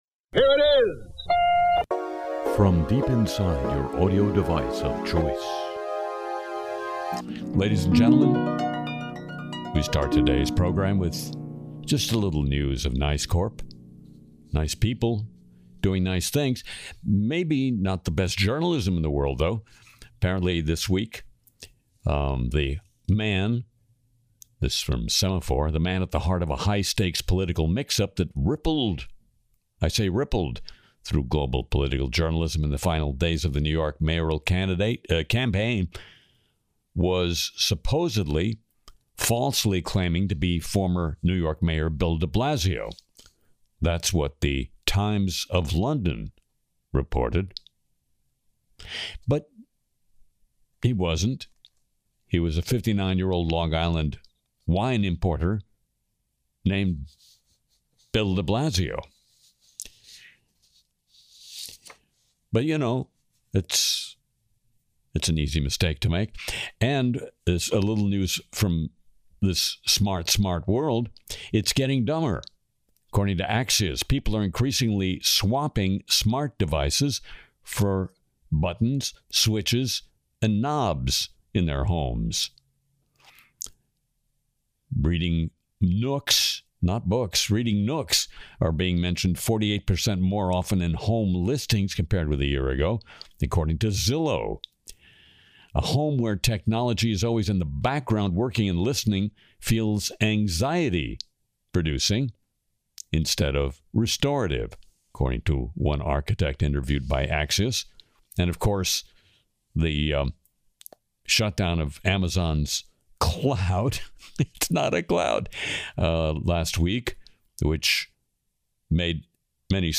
Harry Shearer’s Le Show (Nov 2 2025) skewers dumb homes, AI social media brain rot, Grokipedia, a Trump skit, and a Stephen Miller parody song